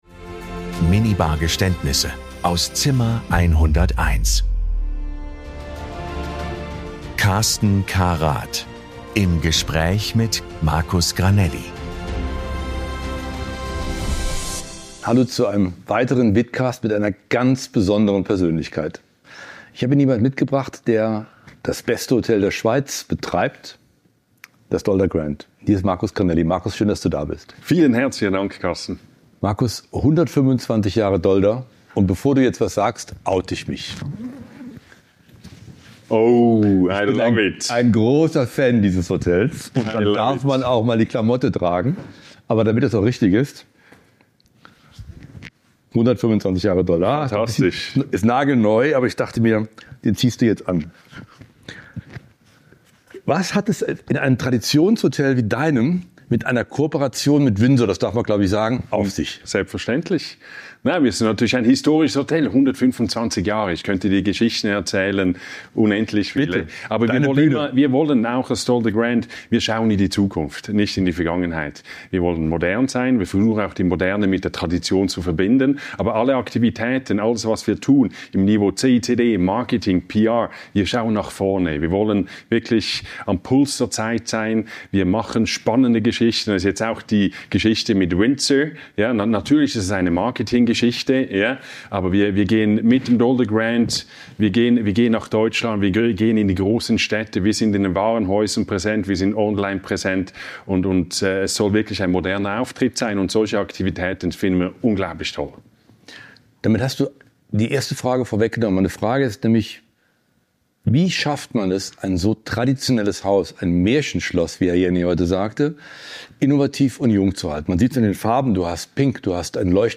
Es wird gelacht, reflektiert und manchmal auch gestanden. Hier wird Hospitality persönlich.
Ein Gespräch über Führung, Haltung und die Kunst, ein Grand Hotel in die Zukunft zu führen.